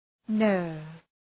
Shkrimi fonetik {nɜ:rv}